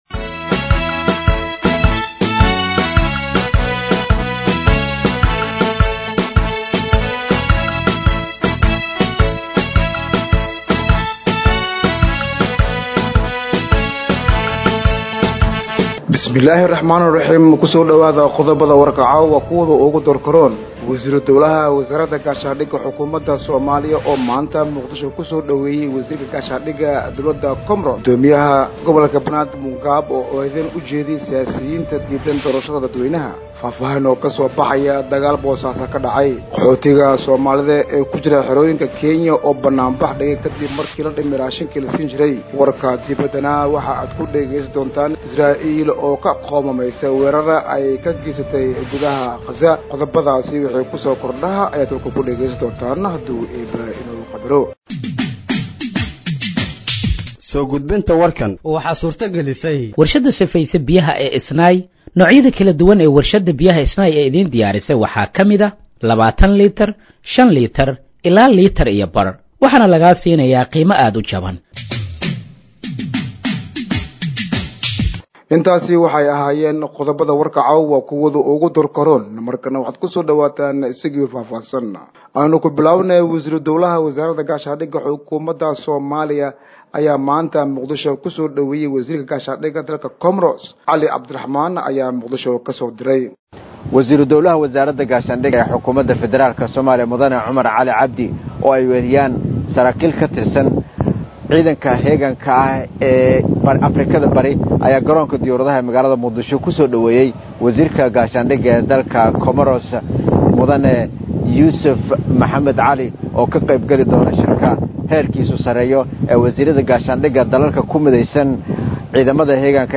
Dhageeyso Warka Habeenimo ee Radiojowhar 18/07/2025